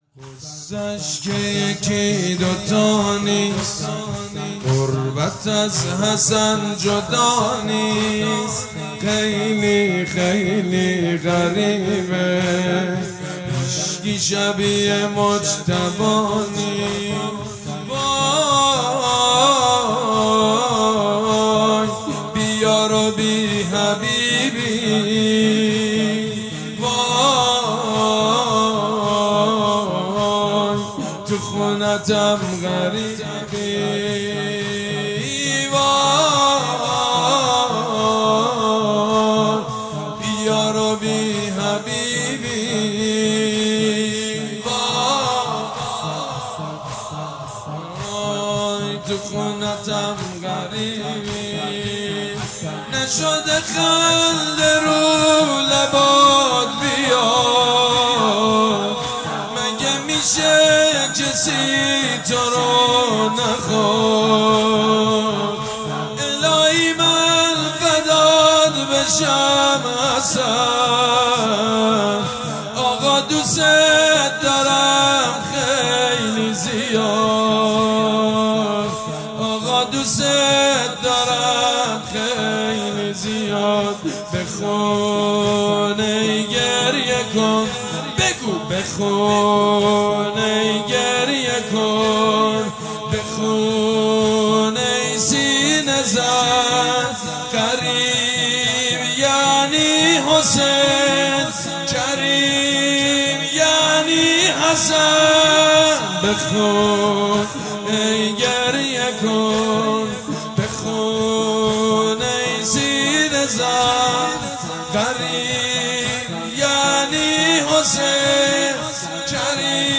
madahi.mp3